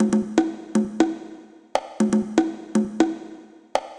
120_conga_1.wav